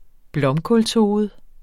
Udtale [ ˈblʌmkɔls- ]